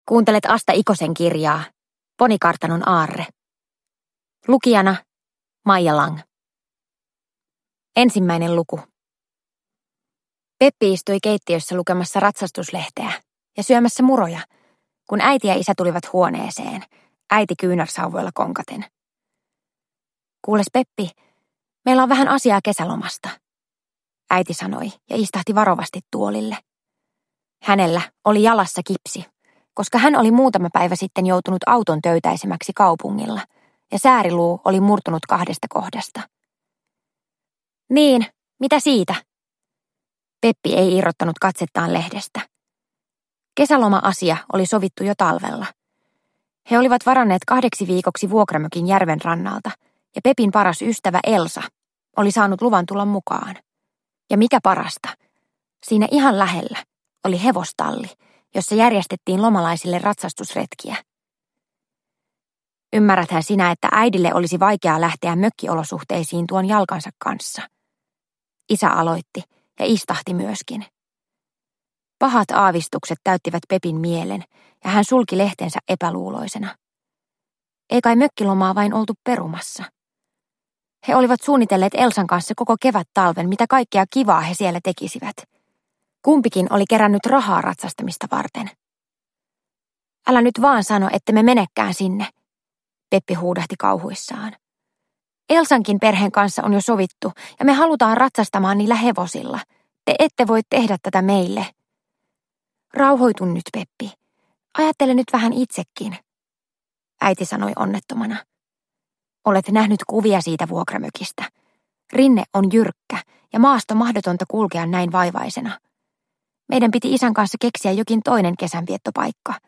Ponikartanon aarre – Ljudbok – Laddas ner